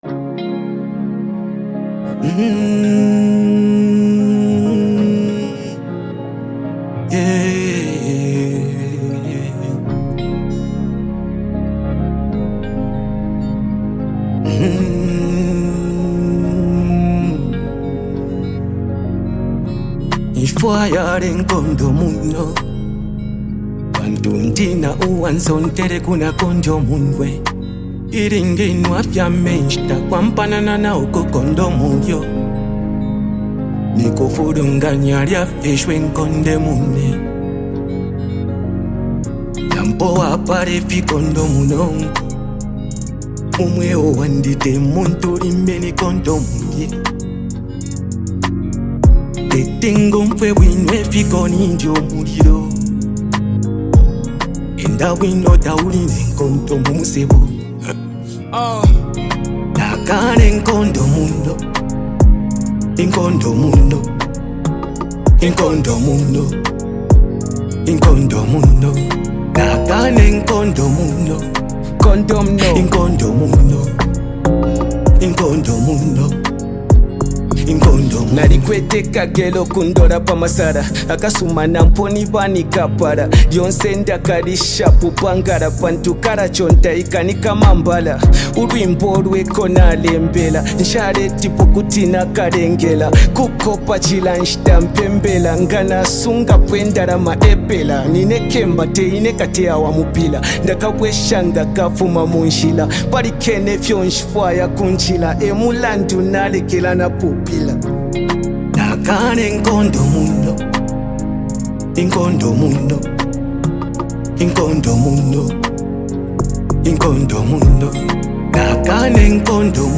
powerful and emotionally charged song
combined with a reflective instrumental